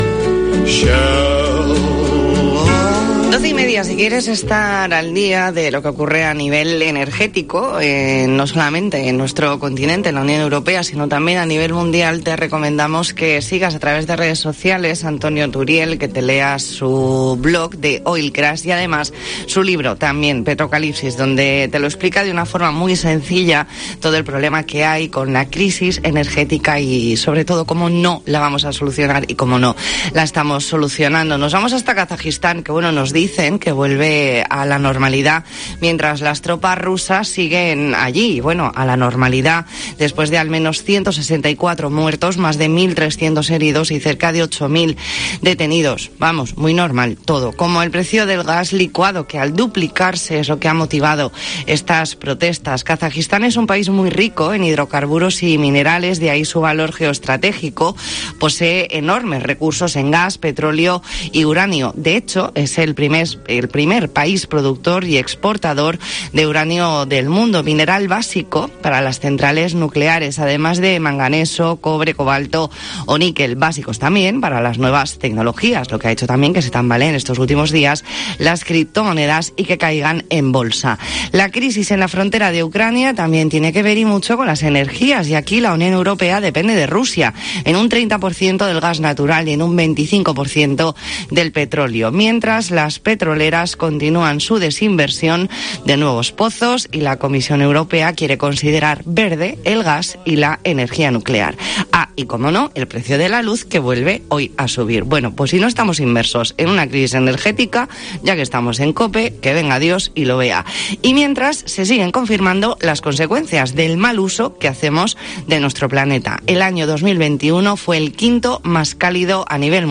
Entrevista en La Mañana en COPE Más Mallorca, martes 11 de enero de 2022.